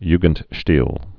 (ygənt-shtēl)